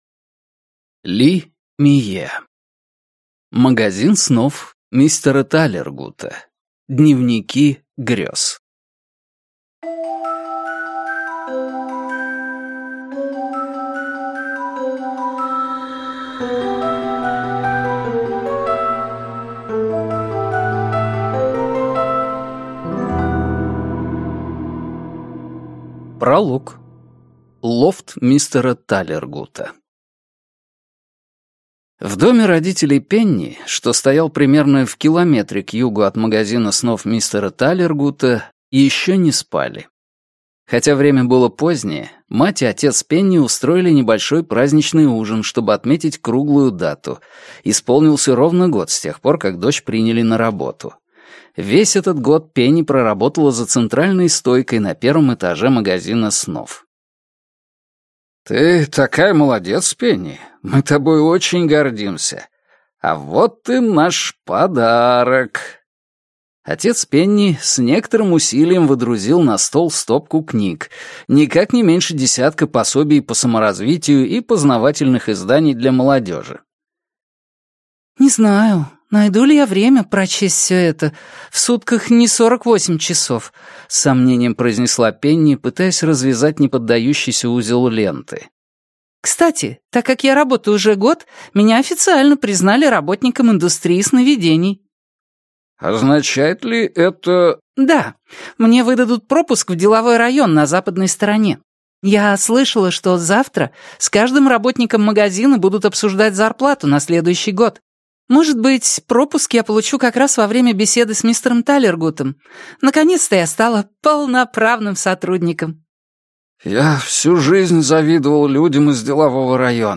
Аудиокнига «Магазин снов» мистера Талергута. Дневники грез | Библиотека аудиокниг